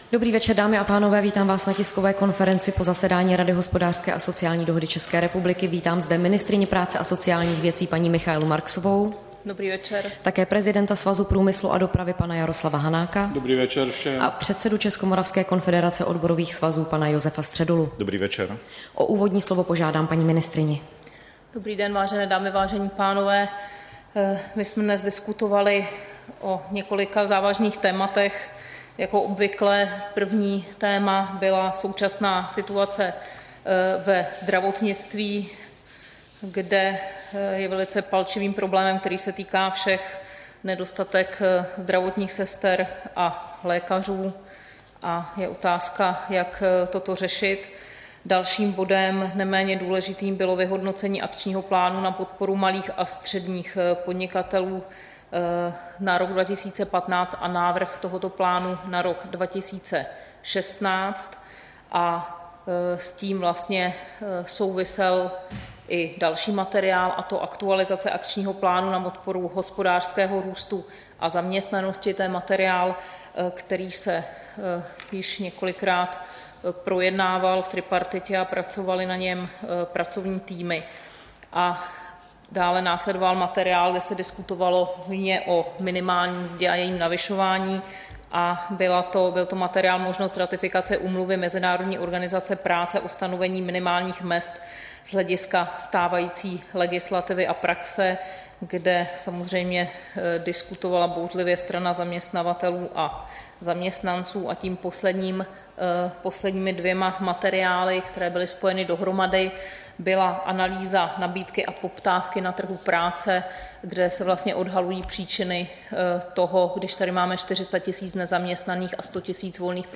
Tisková konference po jednání tripartity, 14. prosince 2015